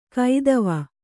♪ kaidava